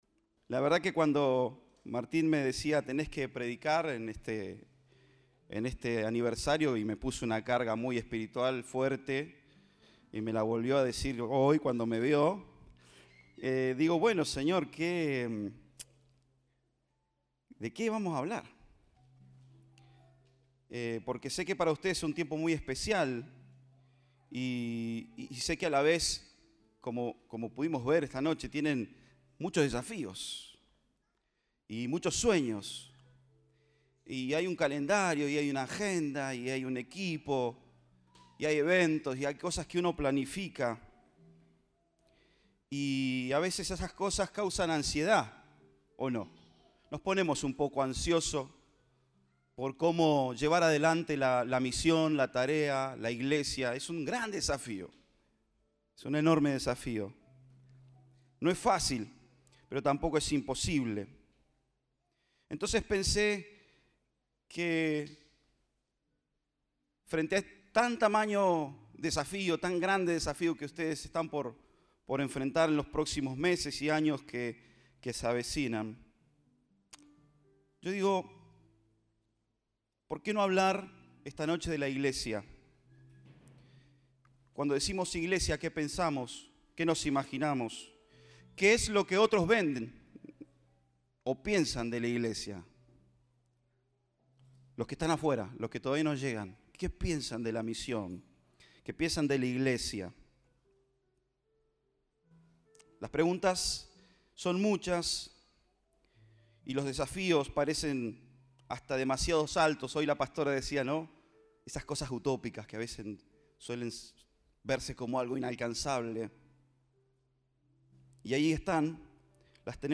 Compartimos el mensaje del Domingo 27 de Marzo de 2022.